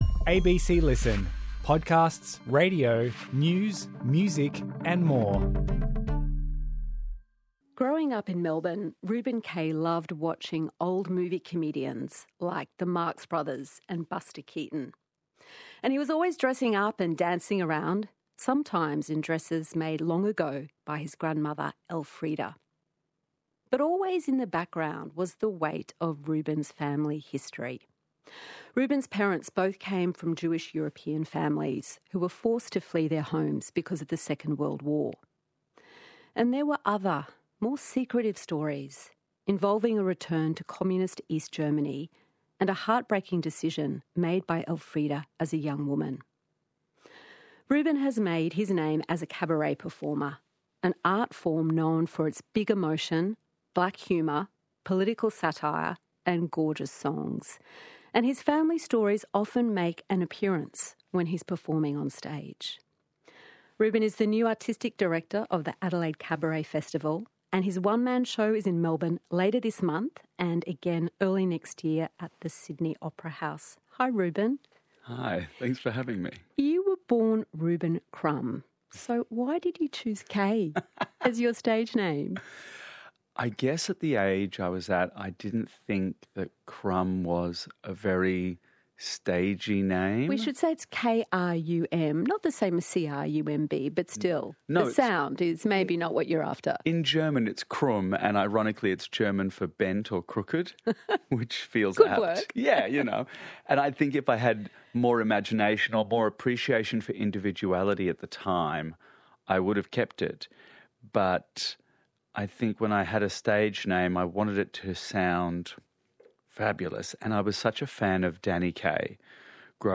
Hosted by Richard Fidler and Sarah Kanowski, Conversations is the ABC's most popular long-form interview program.